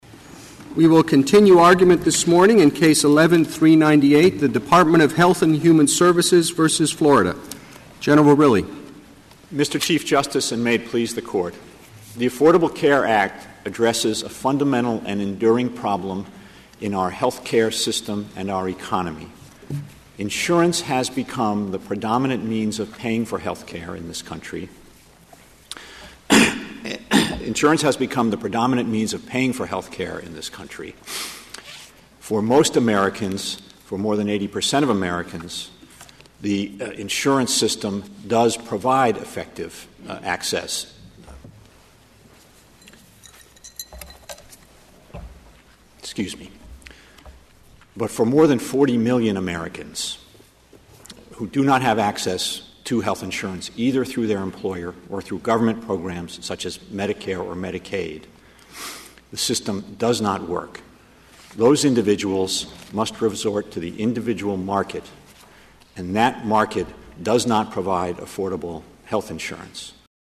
Tuesday, March 27, 2012, was the second of three days of oral argument in the most important Supreme Court case of the Obama administration: the challenge to the Affordable Care Act’s individual mandate. When Solicitor General Donald Verrilli stepped to the podium that morning, with the fate of the administration’s signature achievement at stake, the weeks of preparation and practice had taken their toll on his voice.
Verrilli recalled in a 2016 interview with NPR, “My throat kind of seized up a bit, so I took a sip of water, and it went down the wrong way. And I just literally couldn’t get the words out for a little bit. And not surprisingly, I guess my attention sort of wavered. I lost my focus.” Here’s how the first minute or so of Verrilli’s argument went:
Calling it “one of the most spectacular flameouts in the history of the court,” Mother Jones noted that “Verrilli stammered as he began his argument. He coughed, he cleared his throat, he took a drink of water. And that was before he even finished the first part of his argument.”
Verrilli-ACA-Argument.mp3